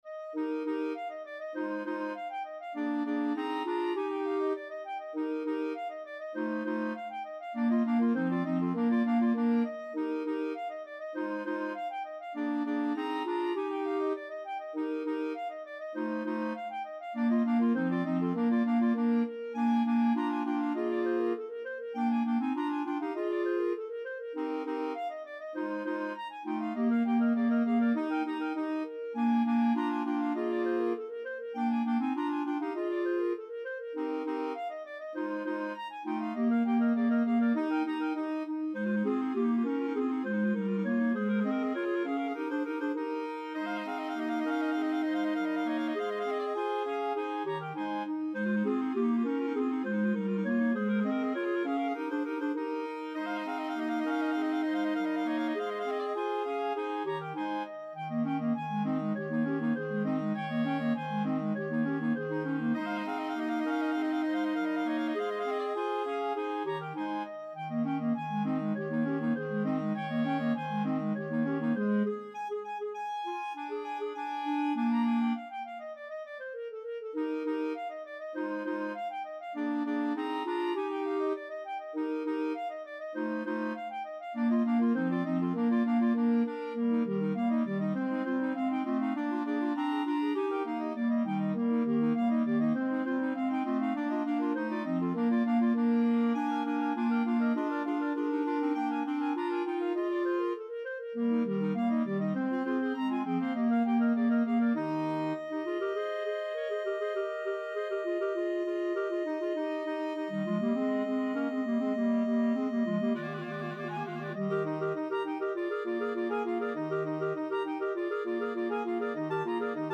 Clarinet 1Clarinet 2Clarinet 3Clarinet 4
2/4 (View more 2/4 Music)
Classical (View more Classical Clarinet Quartet Music)